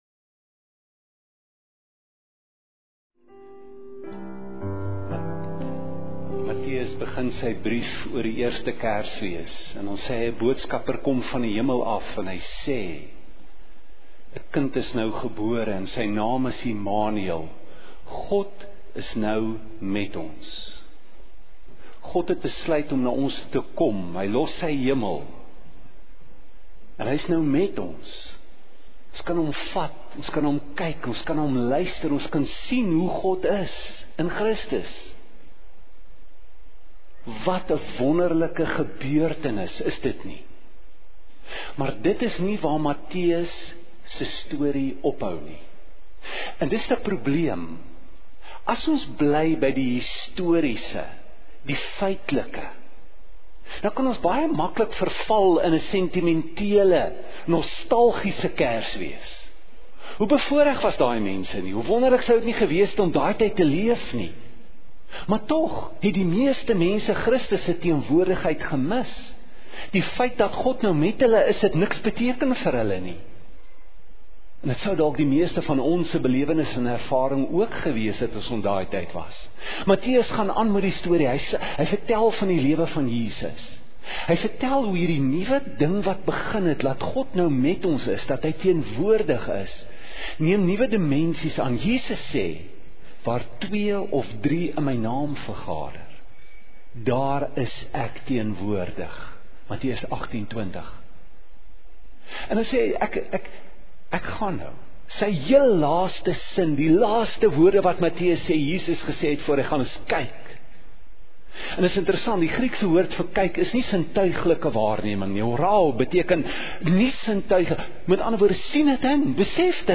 Prediker